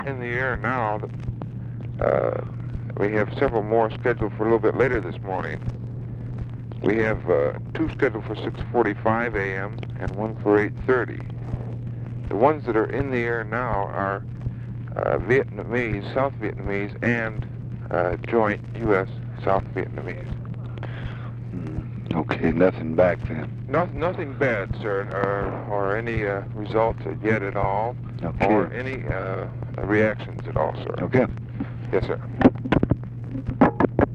Conversation with WH SITUATION ROOM, April 20, 1965
Secret White House Tapes